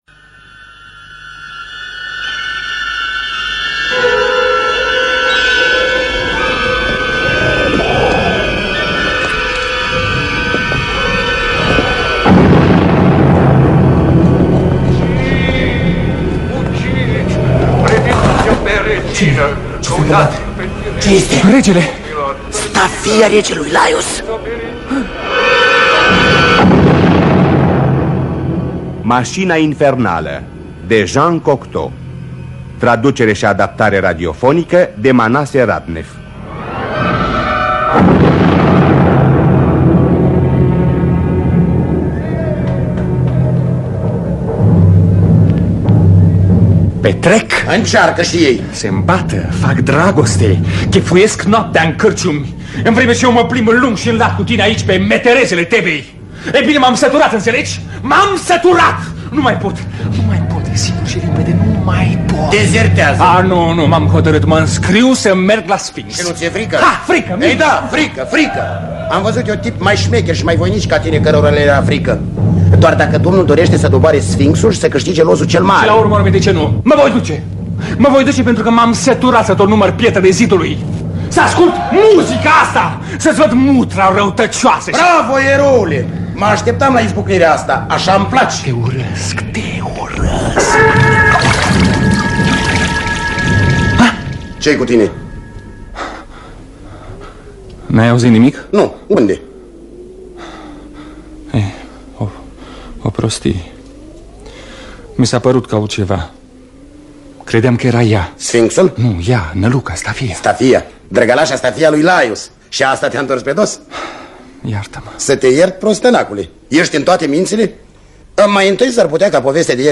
Maşina infernală de Jean Cocteau – Teatru Radiofonic Online